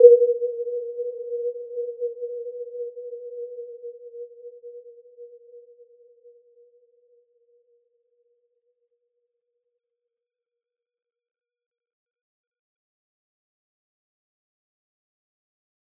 Warm-Bounce-B4-f.wav